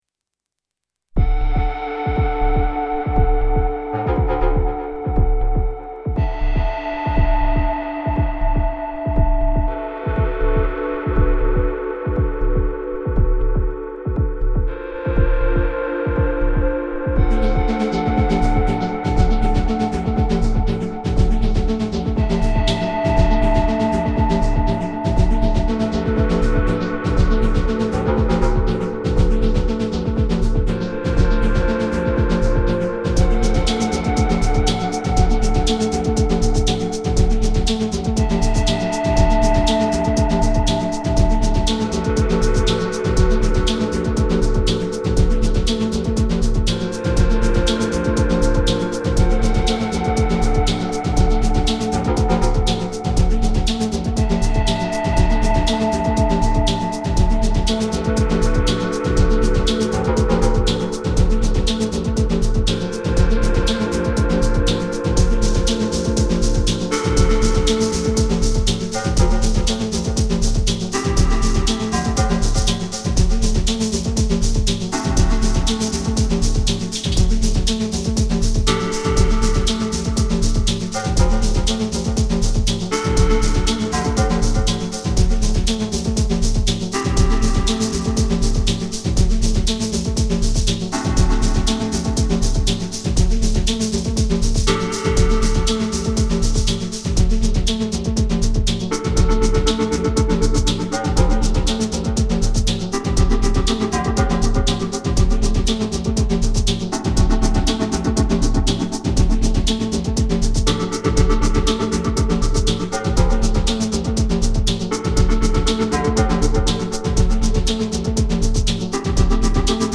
Three sketches.
Straight from the Syntakt. Something’s wrong with my audio interface, apologies for the occasional boiling in the right channel.
the snare in the second track sounds so good. soft and sharp & breathy at the same time. good stuff!